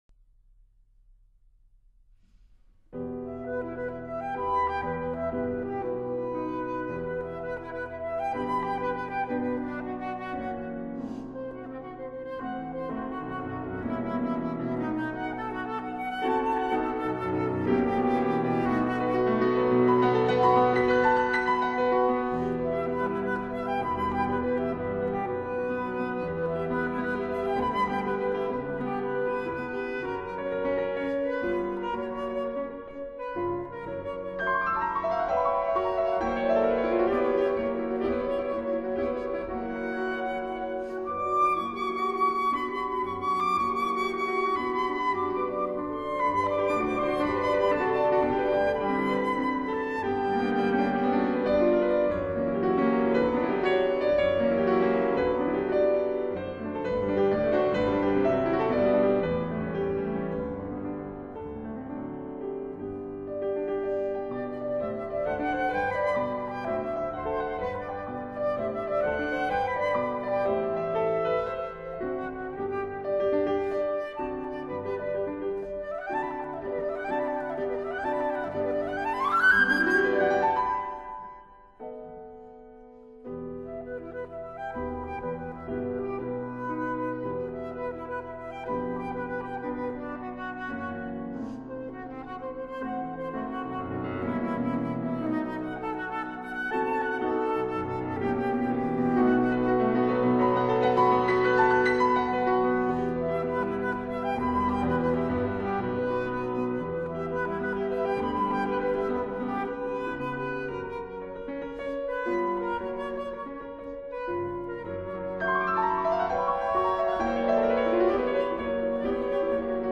flute
piano